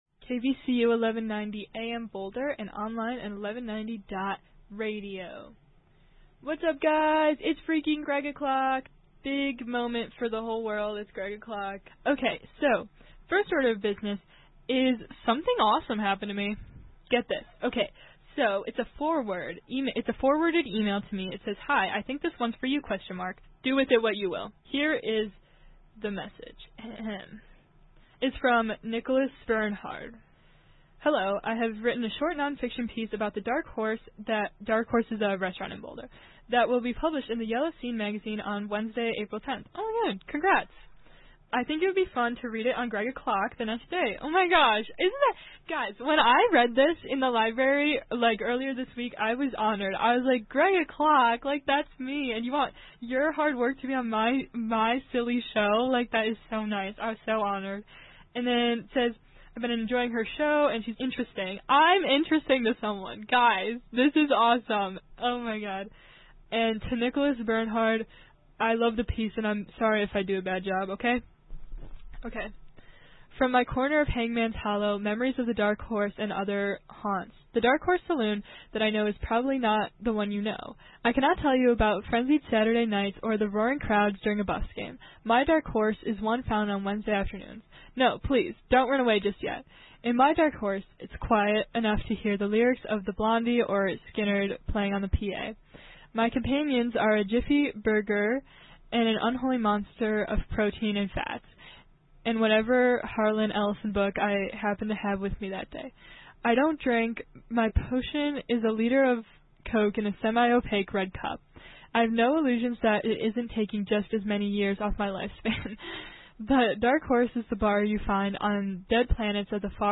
Live reading